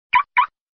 Dzwonek - Blokada zamku samochodowego
Dzwięk elektronicznej blokady zamku samochodwego.
blokada-zamku-samochodowego.mp3